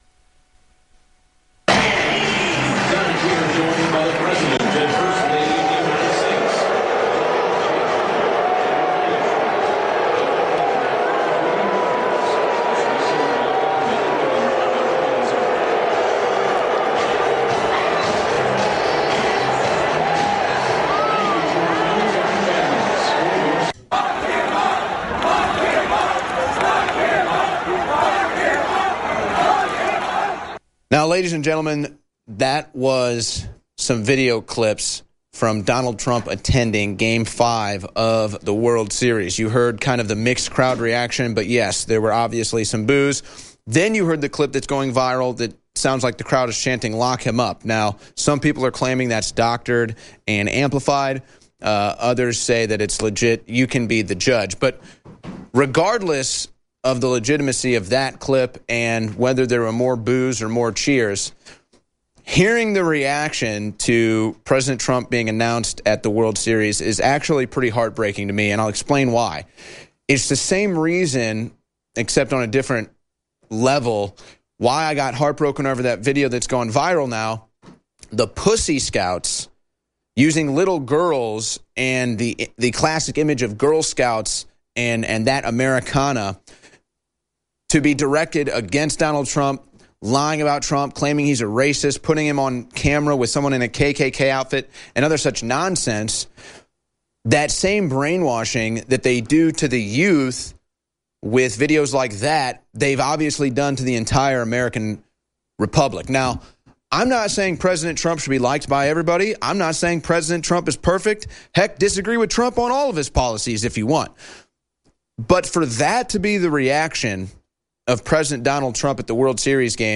Owen Shroyer begins in-studio with a response to Trump being booed at the world series then departs for a "secret mission".
Owen is live on the scene of a climate change protest at UT.